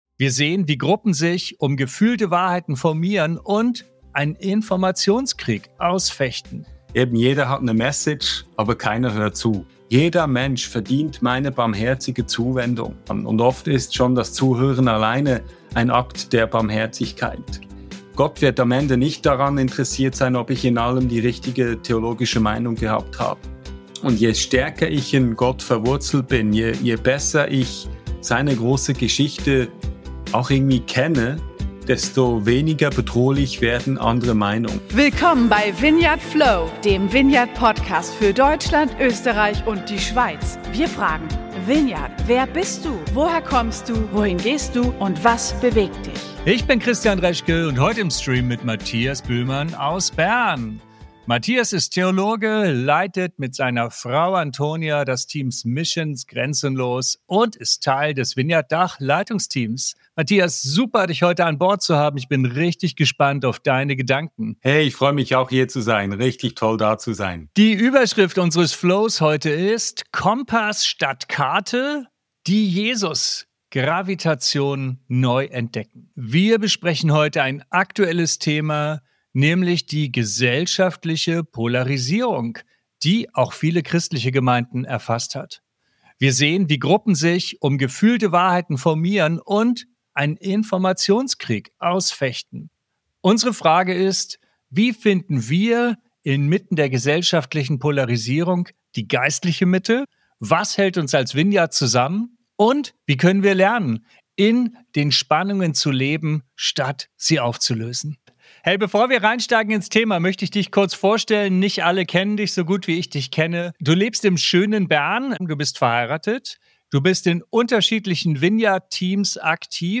Zentrale Themen des Gesprächs sind die Bedeutung der Jesus-Zentrierung, das Vermeiden von Kulturkämpfen und die Pflege von Beziehungen trotz unterschiedlicher Meinungen. Welche Rolle spielt die „radical middle“?